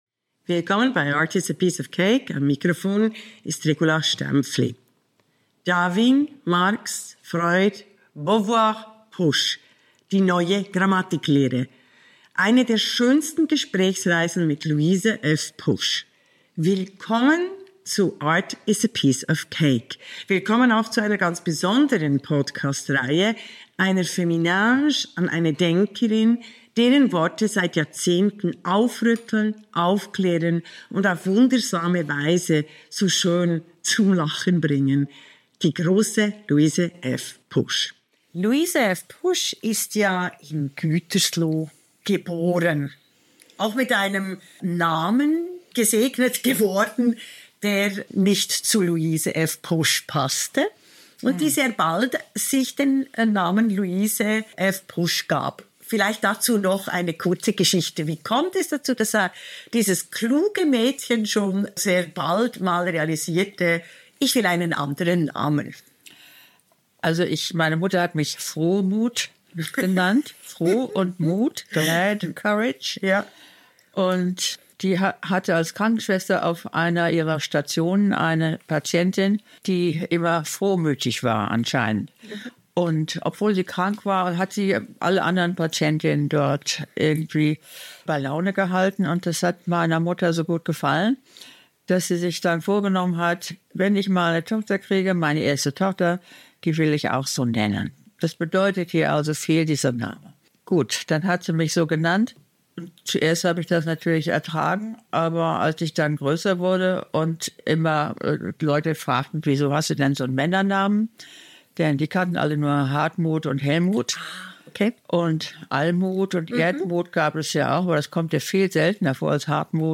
Luise F. Pusch: Grammatik von Macht & Poesie der lesbischen Liebe. Eine feministische Gesprächsreise